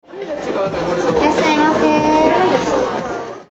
前ページのメロディアスな「歌うように」タイプの正反対のタイプが以下である。
●平坦タイプNo.2●
感情を排した徹底したモノトーンな節回しは、もはや集客のための呼び込みという機能から脱皮し、むしろ「いらっしゃいませFEMMEとはなにか」、という根源的問いかけを我々に投げかけているのである。